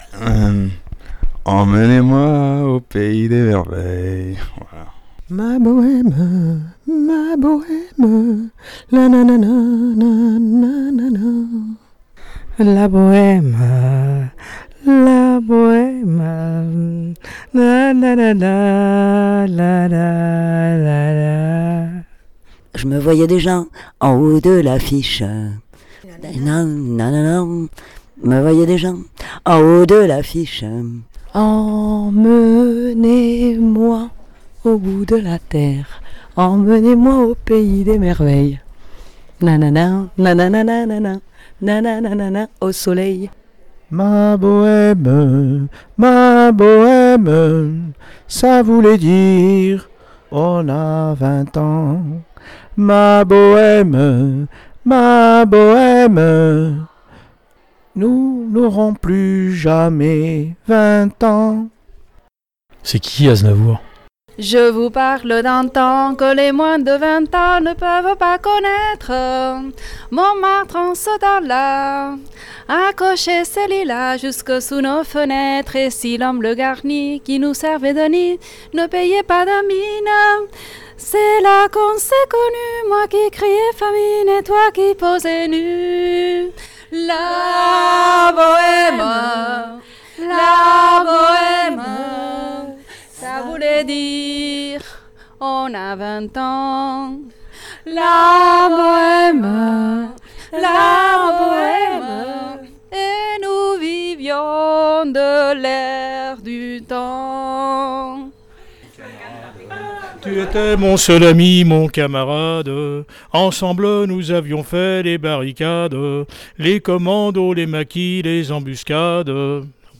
Le micro de la Web Radio s'est baladé dans les couloirs. Voici les enseignants et la direction qui nous interprètent quelques chansons du grand Charles.
Les enseignants et la direction